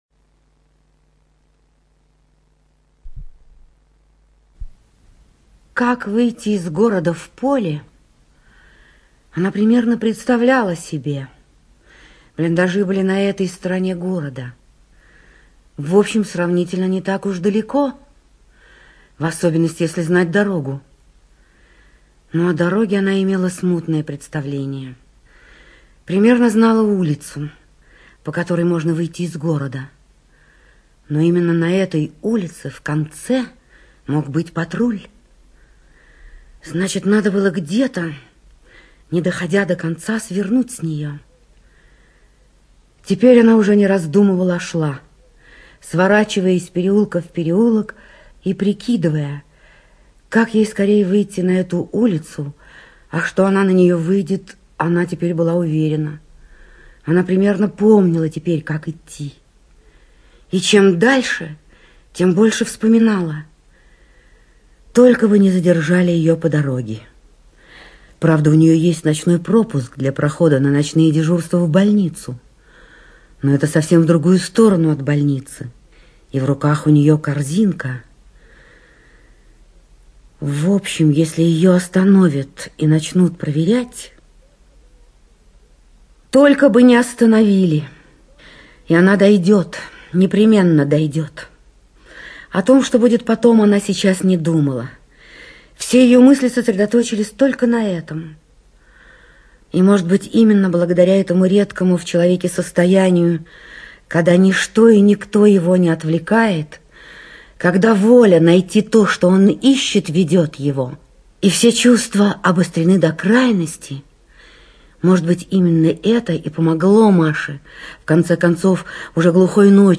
ЧитаетКасаткина Л.